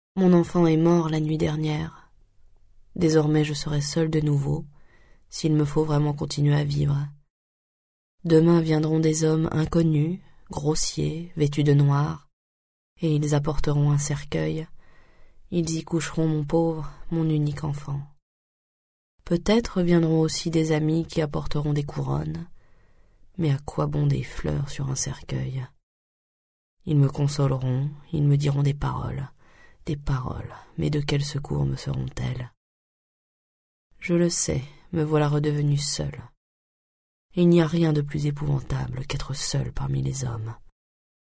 Extrait gratuit - Lettre d'une inconnue de Stefan Zweig
0% Extrait gratuit Lettre d'une inconnue de Stefan Zweig Éditeur : Audiolib Paru le : 2009 Préface écrite et lue par Elsa Zylberstein Un amour total, passionnel, désintéressé, tapi dans l’ombre, n’attendant rien en retour que de pouvoir le confesser.